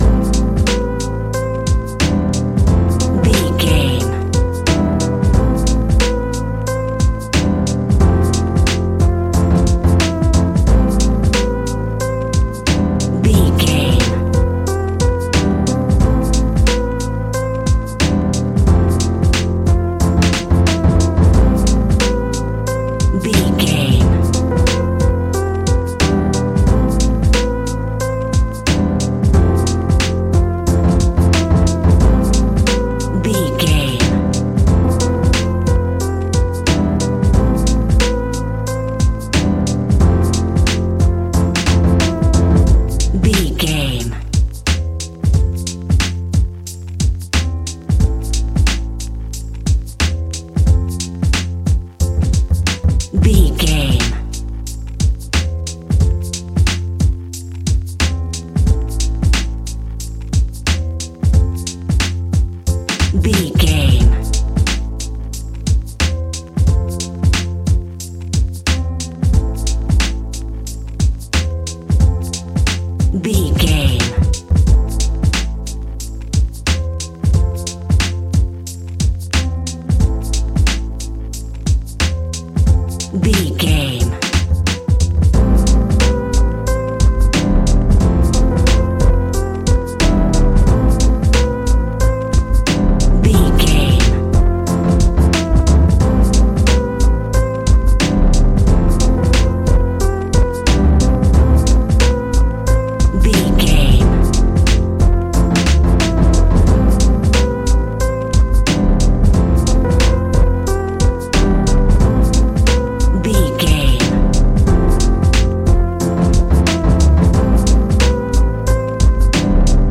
Ionian/Major
chilled
laid back
Lounge
sparse
new age
chilled electronica
ambient
atmospheric
instrumentals